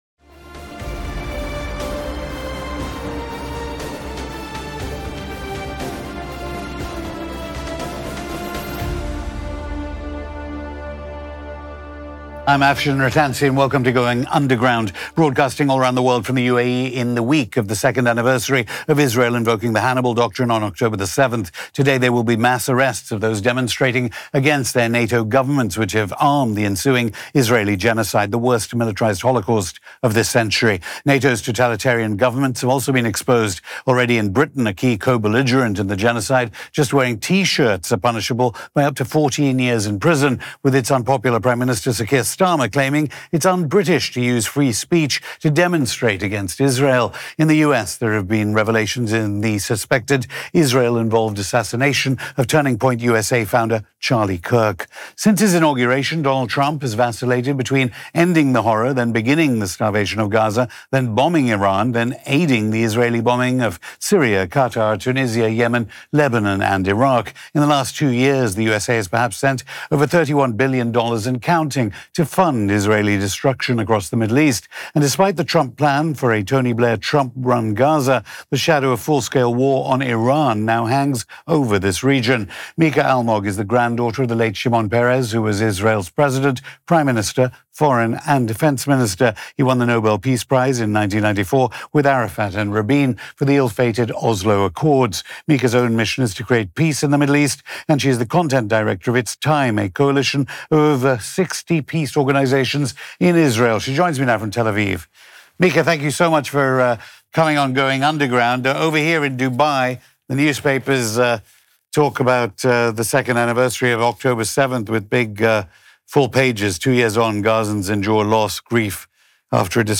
Going Underground Hosted by Afshin Rattansi